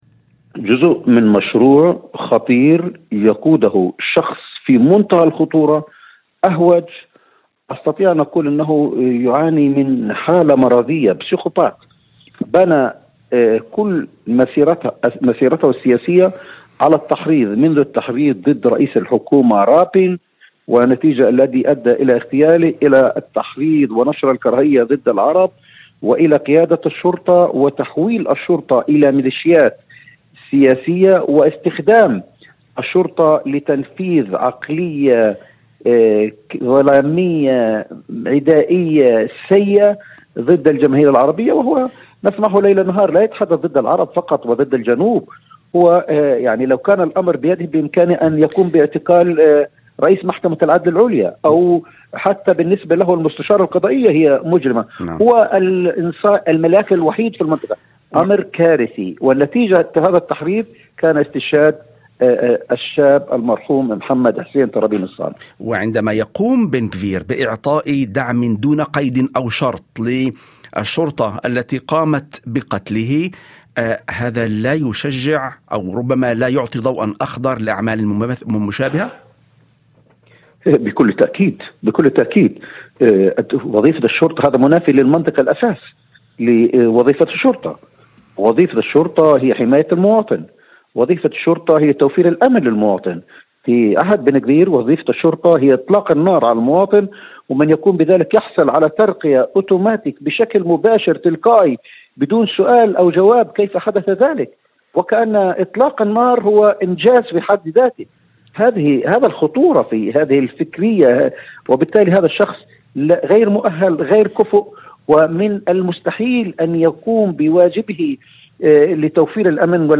وأضاف في مداخلة هاتفية لبرنامج "يوم جديد"، على إذاعة الشمس، أن التحريض المستمر ضد العرب، وخاصة في الجنوب، أدى إلى تآكل الدور الأساسي للشرطة، وتحويلها من جهة يفترض أن تحمي المواطنين إلى قوة تستخدم العنف وتكافئ عليه، محملًا وزير الأمن القومي إيتمار بن غفير مسؤولية مباشرة عن تصاعد هذا النهج.